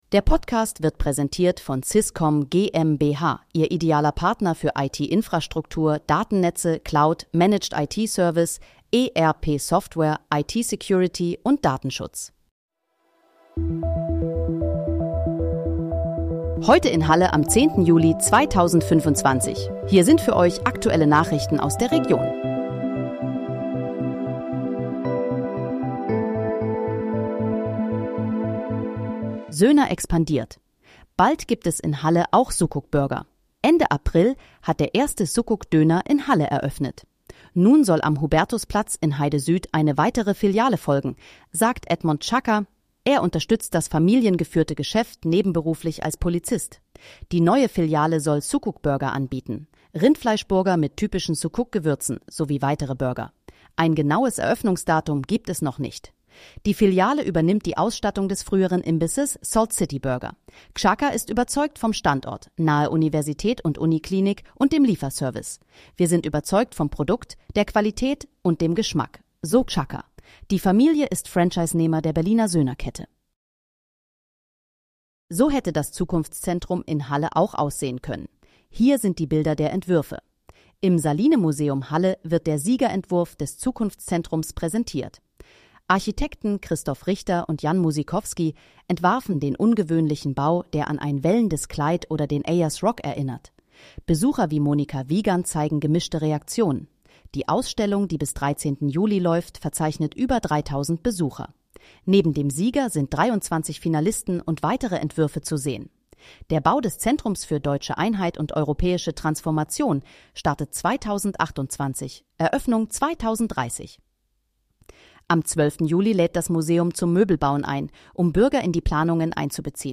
Heute in, Halle: Aktuelle Nachrichten vom 10.07.2025, erstellt mit KI-Unterstützung
Nachrichten